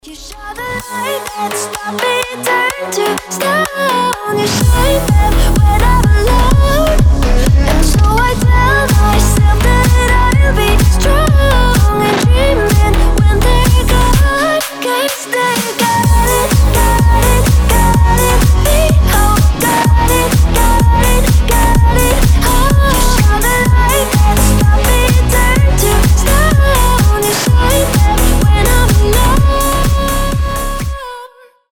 • Качество: 320, Stereo
громкие
EDM
future house
красивый женский голос
slap house